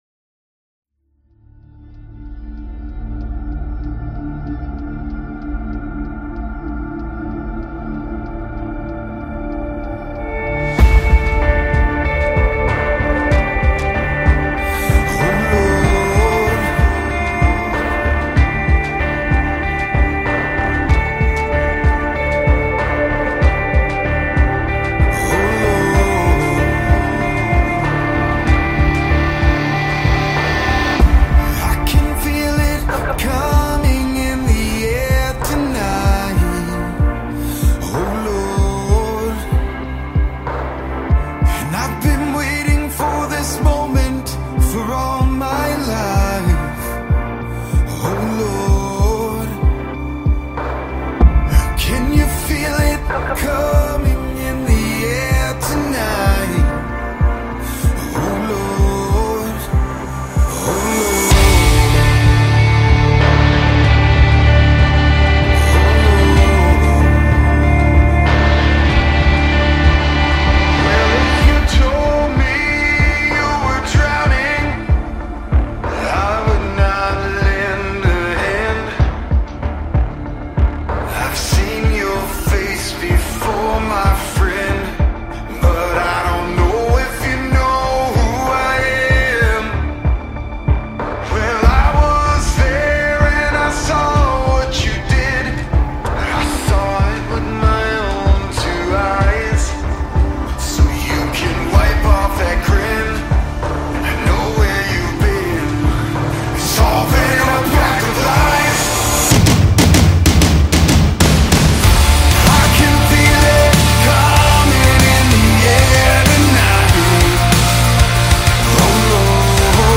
ROCK Cover